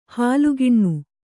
♪ hālu giṇṇu